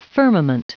Prononciation du mot firmament en anglais (fichier audio)
Prononciation du mot : firmament
firmament.wav